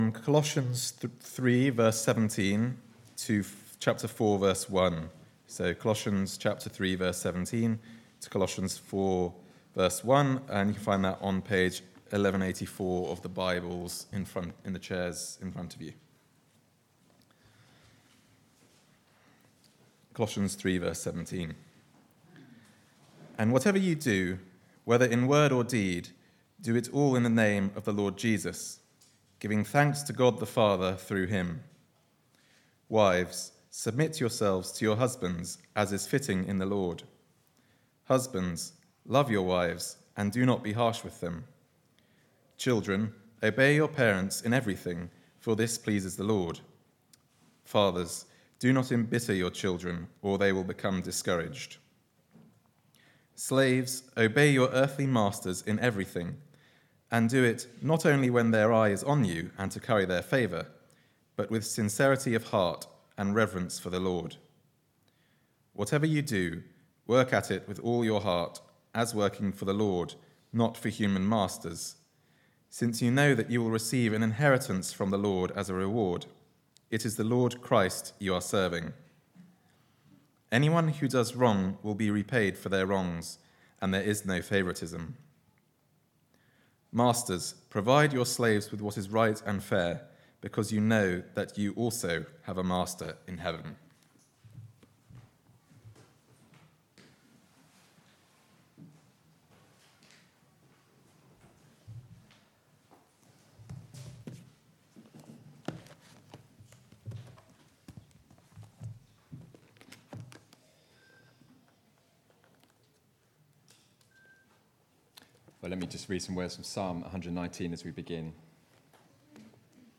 14th-Nov-Sermon.mp3